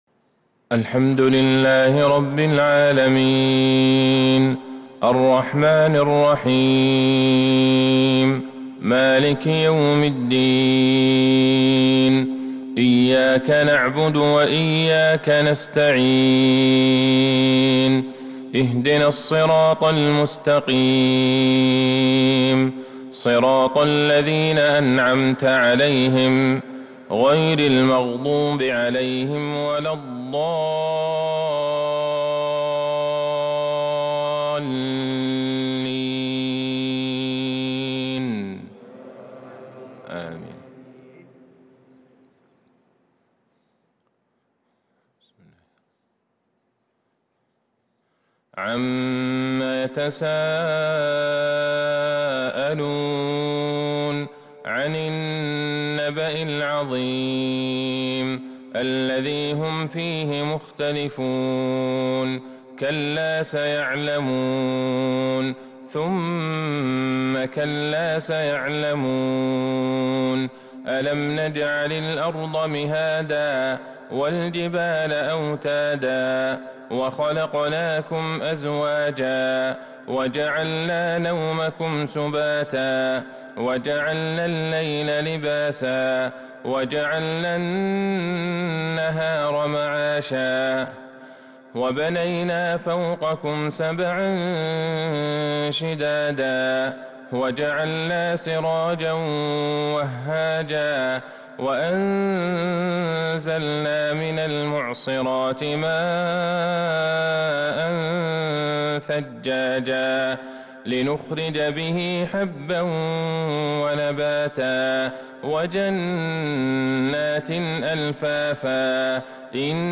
فجر الأربعاء | ٢٥ شعبان ١٤٤٢هـ | سورتي النبأ والشمس | Fajr prayer from Surah An-Naba’ & Ash-Shams 7-4-2021 > 1442 🕌 > الفروض - تلاوات الحرمين